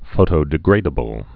(fōtō-dĭ-grādə-bəl)